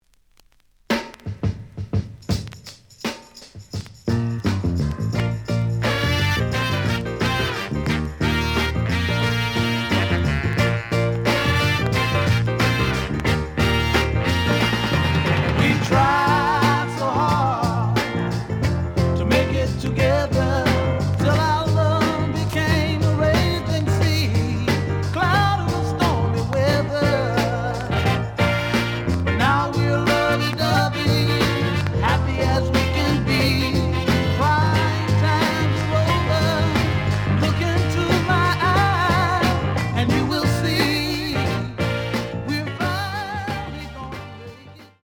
The audio sample is recorded from the actual item.
●Format: 7 inch
●Genre: Soul, 60's Soul